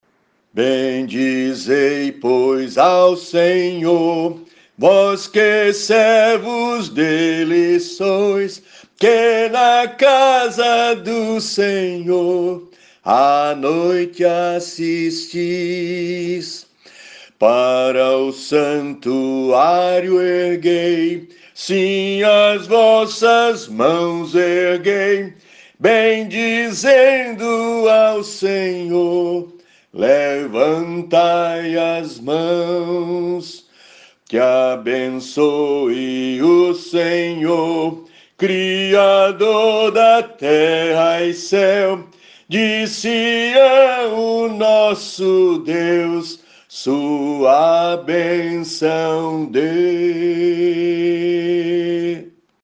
Downloads Áudio Áudio cantado (MP3) Áudio instrumental (MP3) Áudio instrumental (MIDI) Partitura Partitura 4 vozes (PDF) Cifra Cifra (PDF) Cifra editável (Chord Pro) Mais opções Página de downloads
salmo_134B_cantado.mp3